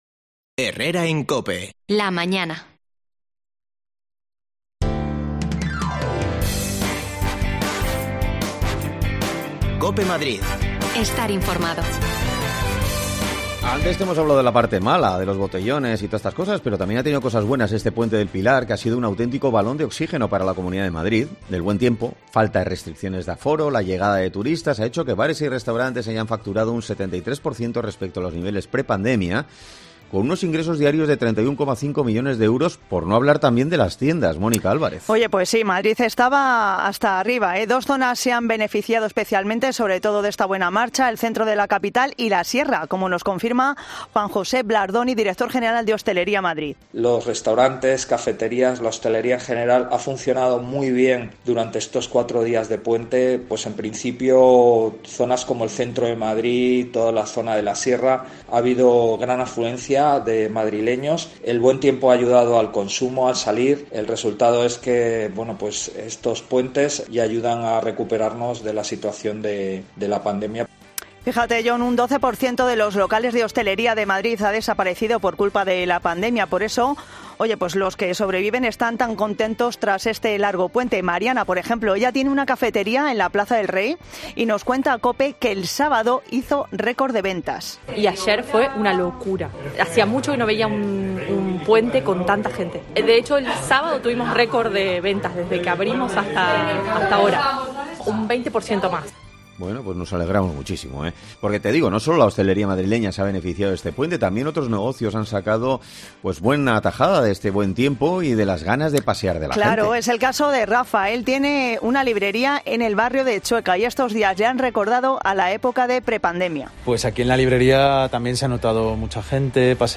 Nos damos un paseo por Madrid para hablar con dueños de estos negocios